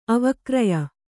♪ avakraya